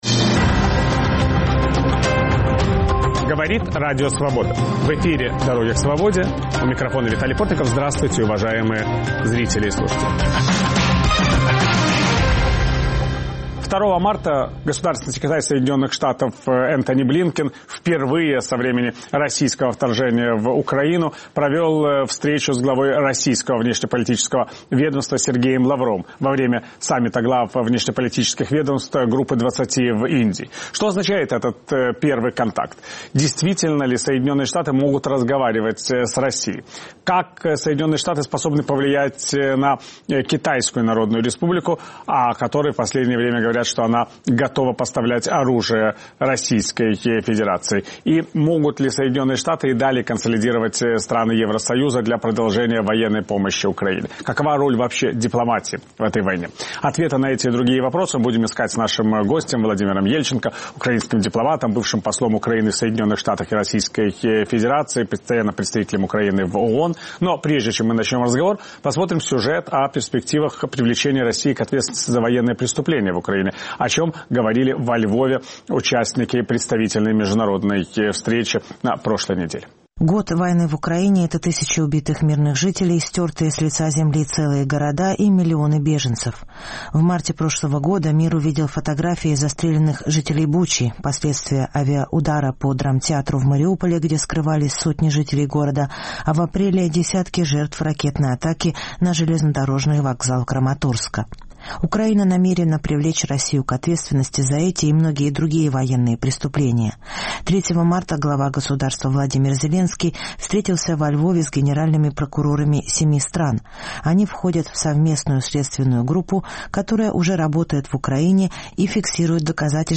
Закрытые двери переговоров. В эфире бывший посол Украины в США и России Владимир Ельченко